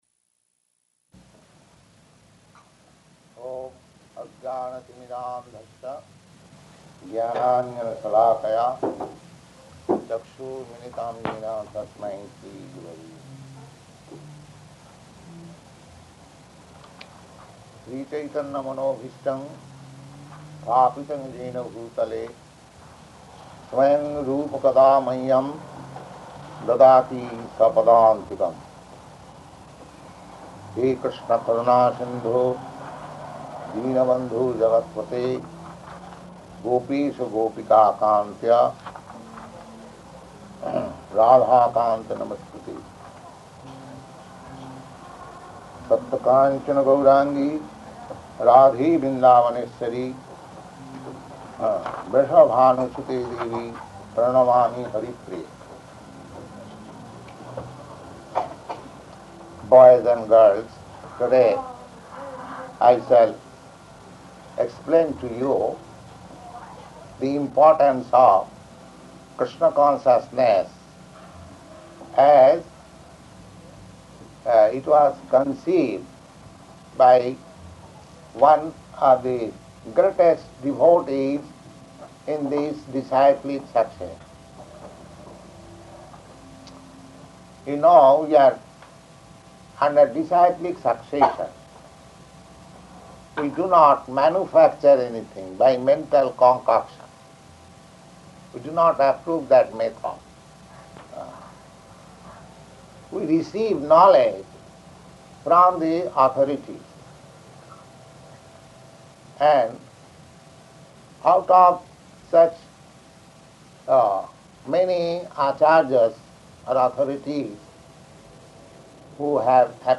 Location: New York
[chants maṅgalācaraṇa prayers]